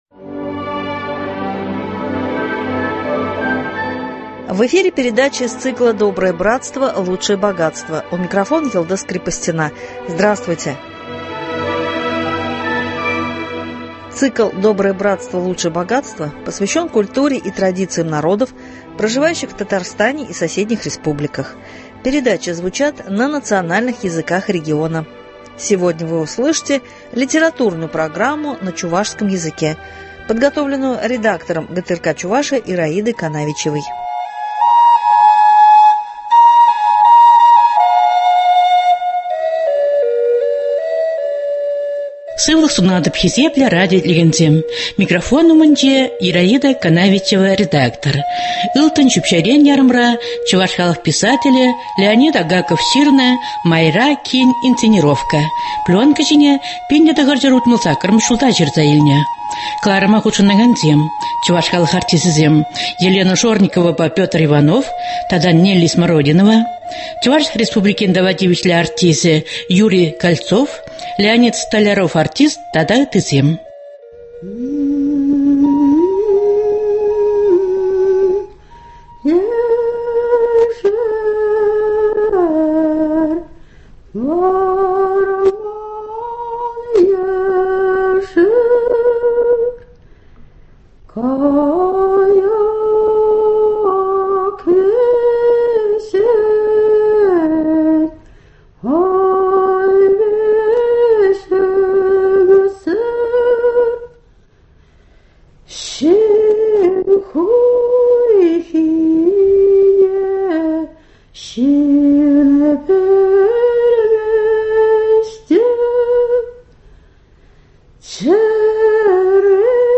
Литературно- музыкальная композиция на чувашском языке.